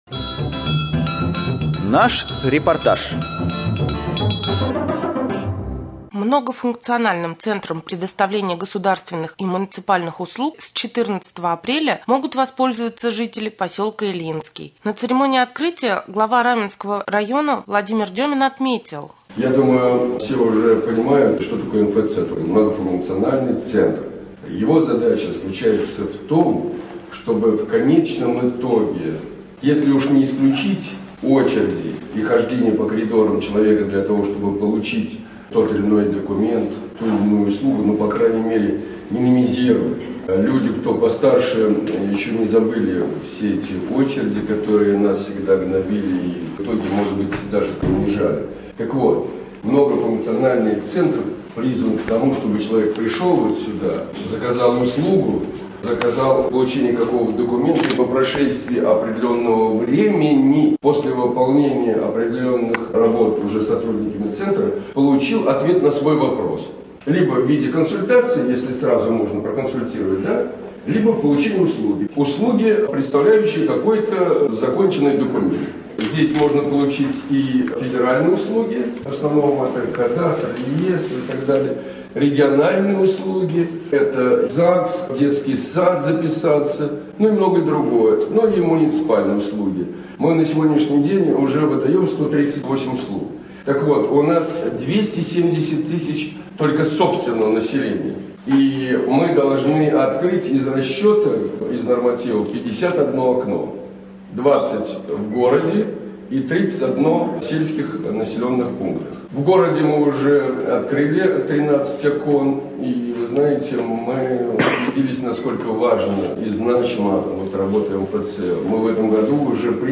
3.Рубрика «Специальный репортаж». В поселке Ильинский открылся МФЦ.